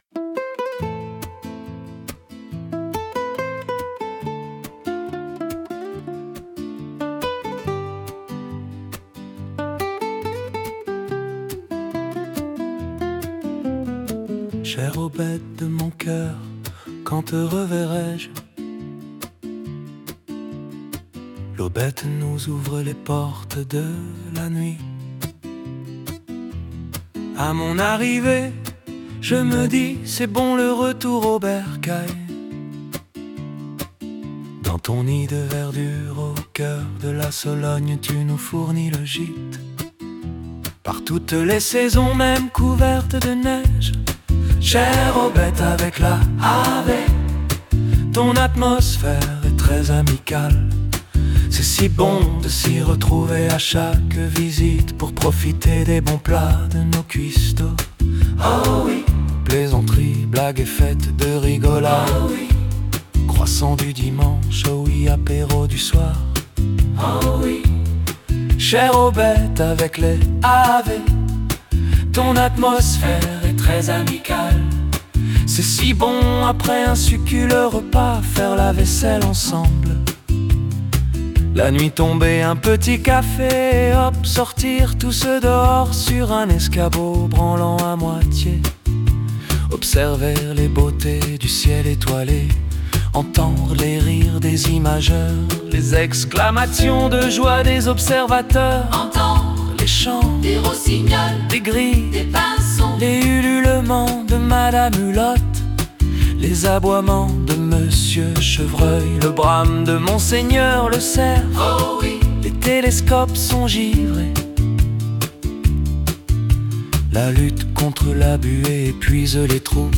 Rock, Passionné, Joyeux, Énergique, Féminin, Masculin, Chœur, Duo, Voix rauque, Profond, Violon, Guitare, Saxophone, Batterie, Piano, 140BPM
une avec dominante guitare
001-aubette-de-mon-coeur-guitare.mp3